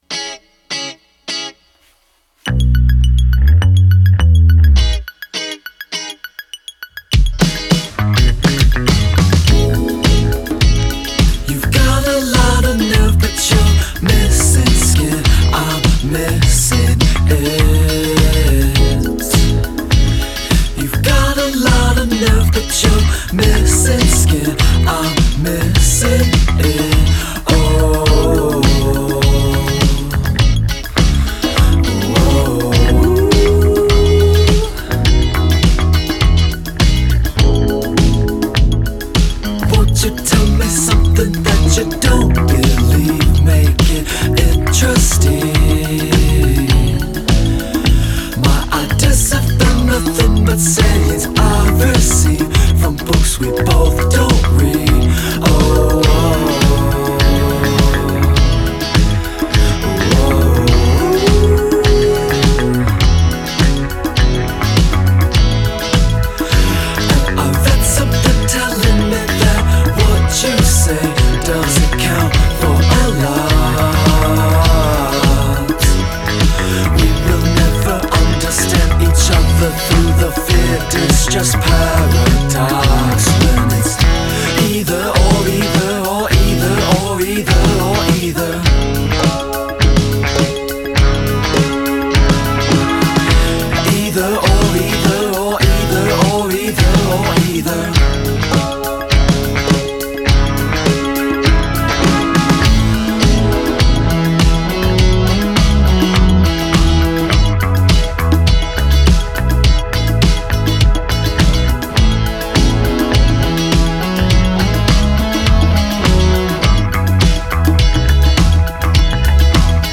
duo américain
pop enjouée flirtant avec l'indie rock